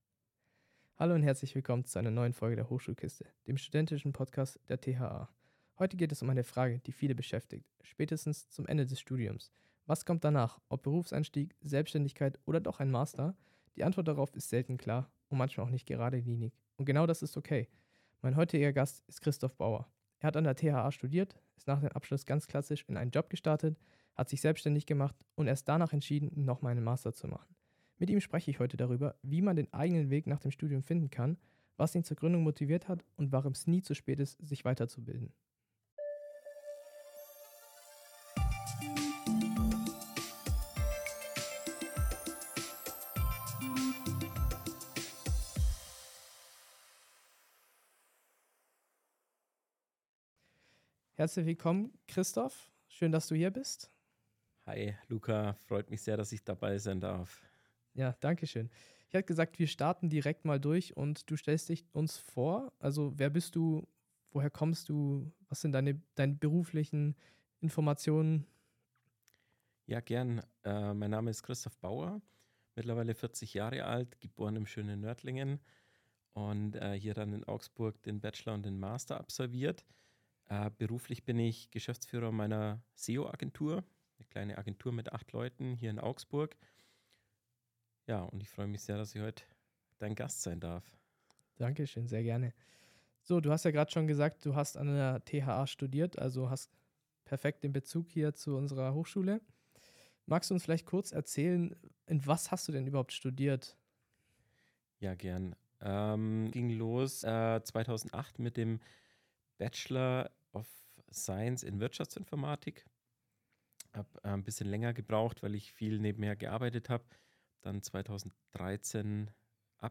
Ein Gespräch über individuelle Wege, bewusste Entscheidungen und die Offenheit für Neues.Was kommt nach dem Studium?